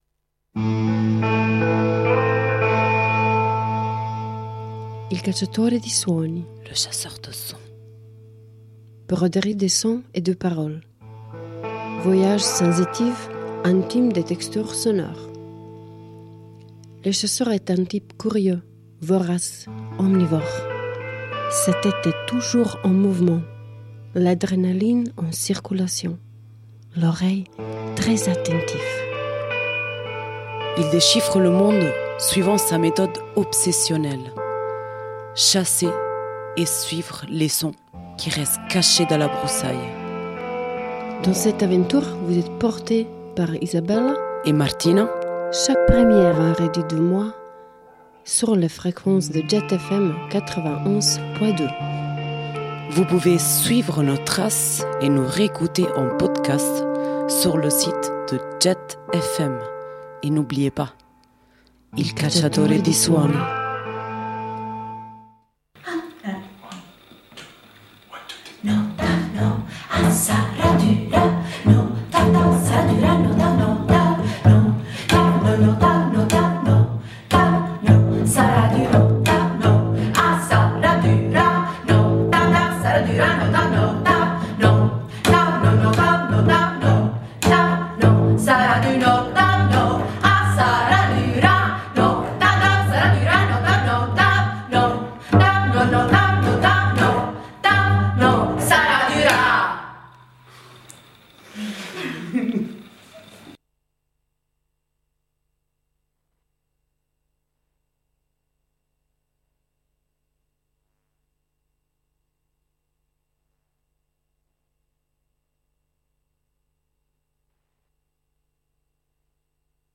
chant
contrebasse